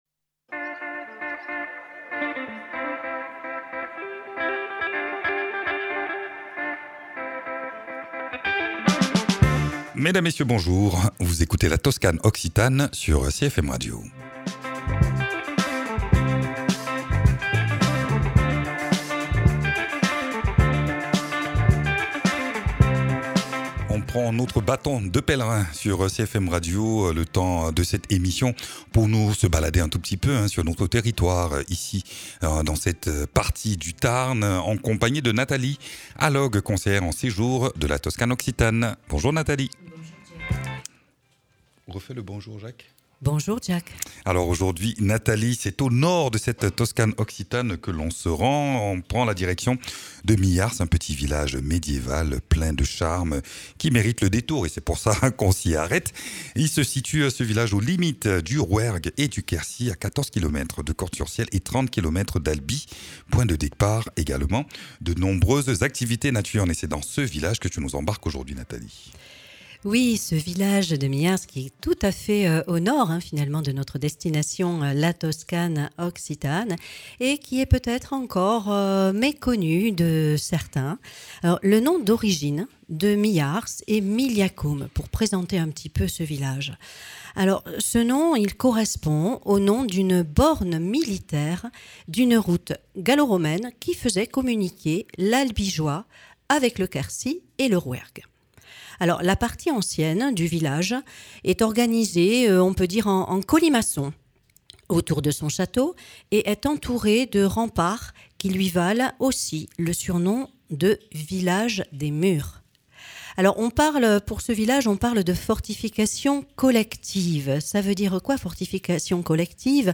Trois de ses membres partagent leur engagement et invitent à découvrir les multiples possibilités créatives et décoratives qu’offre l’osier, au cœur d’un rendez-vous convivial qui fait vivre le village et rassemble habitants